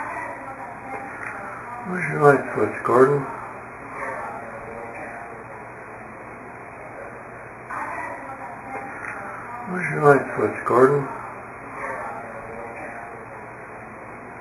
Right after that you hear the response.